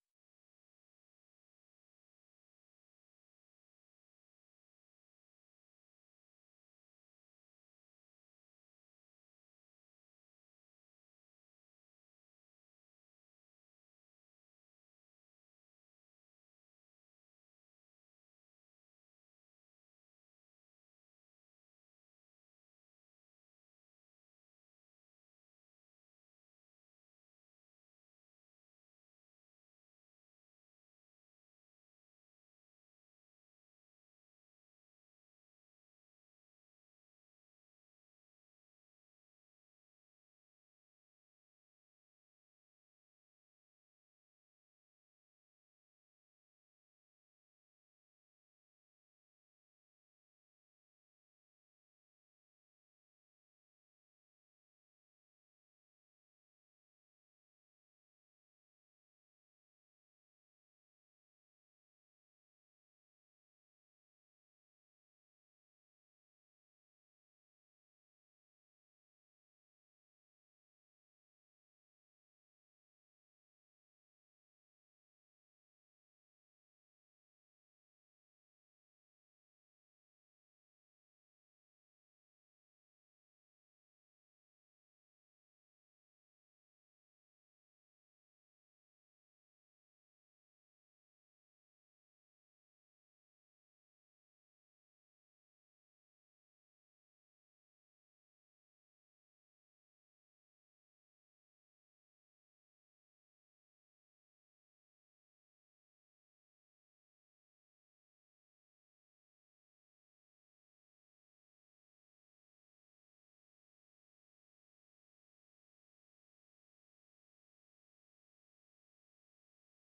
ድምጺ ኣሜሪካ ፈነወ ቛንቛ ትግርኛ ካብ ሶኑይ ክሳብ ሰንበት ይፍነው። ፈነወ ቛንቛ ትግርኛ ካብ ሶኑይ ክሳብ ዓርቢ ብዕለታዊ ዜና ይጅምር፥ እዋናዊ ጉዳያትን ሰሙናዊ መደባት'ውን የጠቓልል ።ቀዳምን ሰንበትን ኣብቲ ሰሙን ዝተፈነው መደባት ብምድጋም ፈነወ ቛንቛ ትግርኛ ይኻየድ።